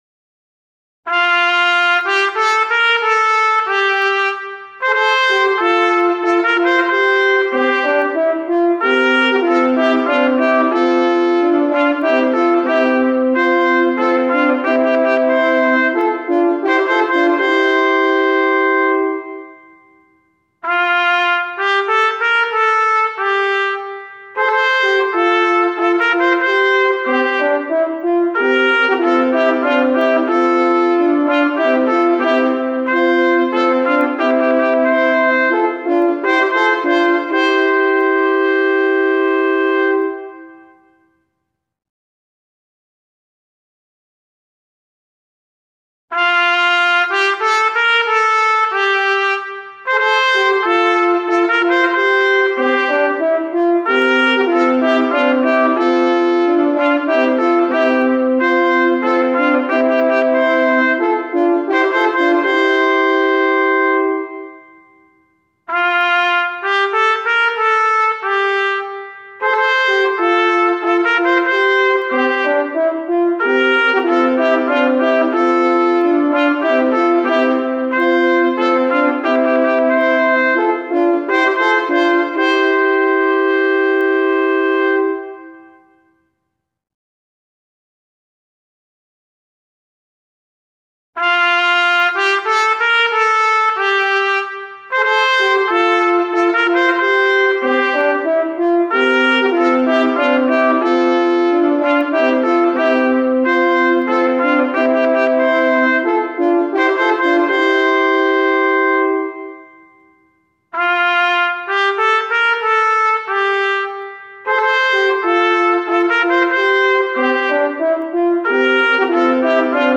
Na żywo jest grana na trąbkę i dwie waltornie.